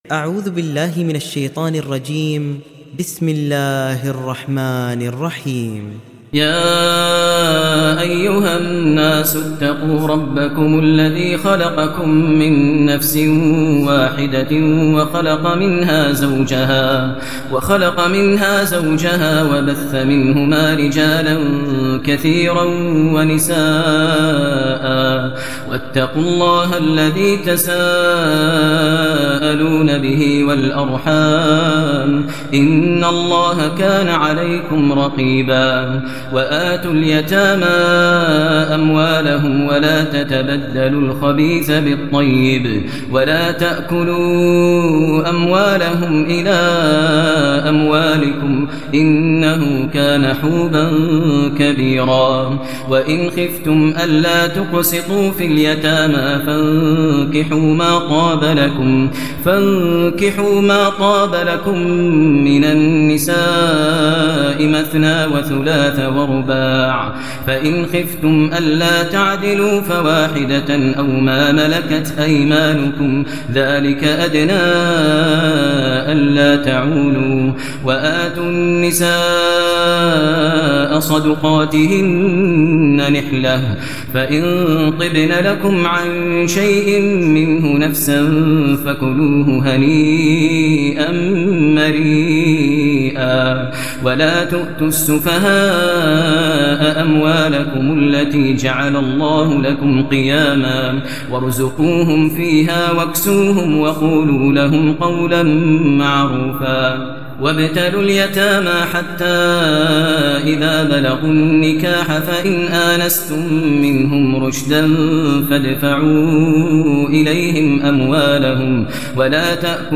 تحميل سورة النساء mp3 بصوت ماهر المعيقلي برواية حفص عن عاصم, تحميل استماع القرآن الكريم على الجوال mp3 كاملا بروابط مباشرة وسريعة